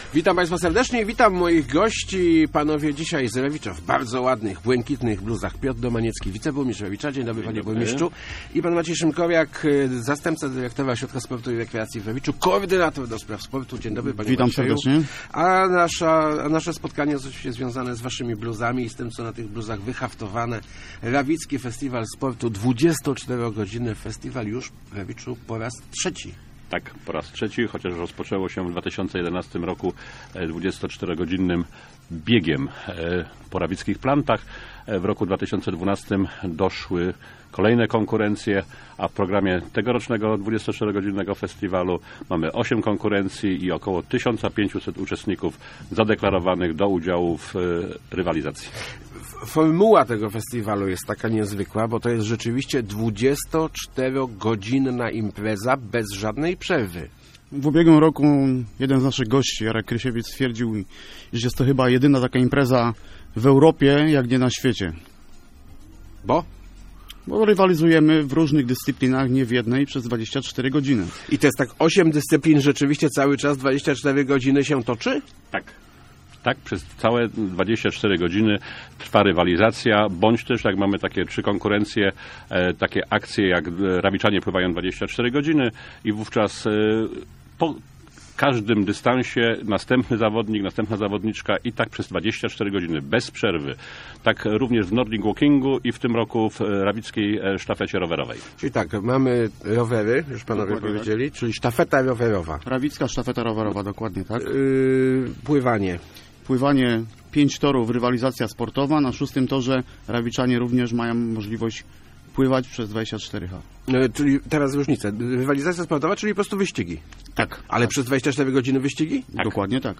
W najbliższy weekend w Rawiczu już po raz trzeci odbędzie się 24-godzinny Festiwal Sportu. Przez całą dobę trwać będą rozgrywki w ośmiu dyscyplinach - mówili w Rozmowach Elki zastępca burmistrza Rawicza Piotr Domaniecki